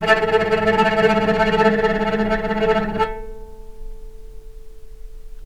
vc_trm-A3-pp.aif